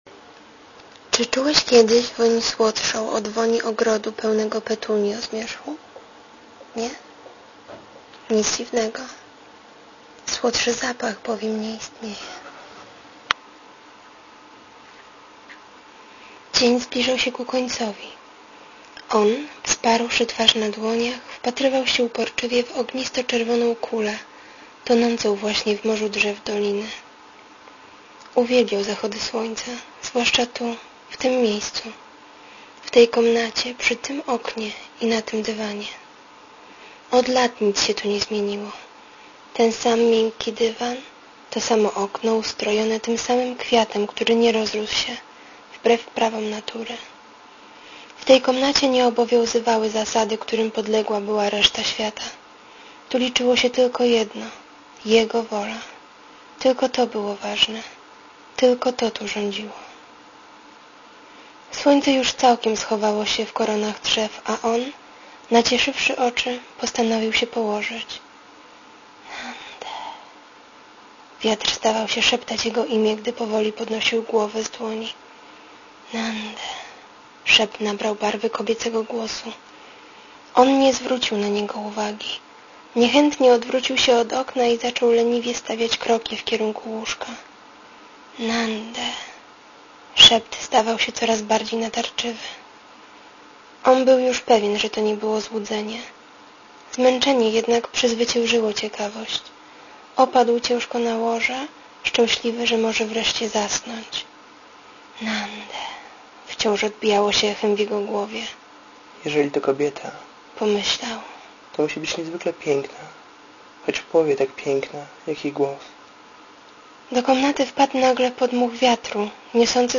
Pomyślałem, że programowo można byłoby polepszyć głos "Nande...".
Moim zdaniem zbyt szybko i gwałtownie kończyłaś to słowo.